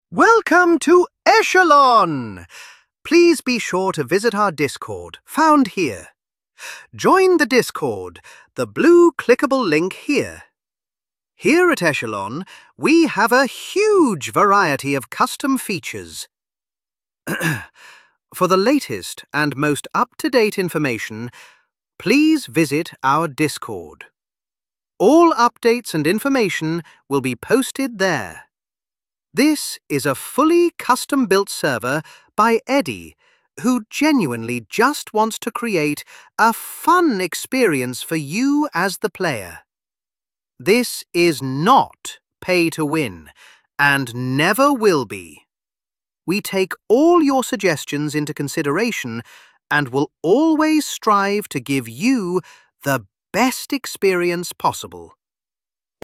AI Voice acting
I have added some AI voice acting into the server.
All of the text written in the book will now have a AI voice backing it so you can not only read the information but hear it as well;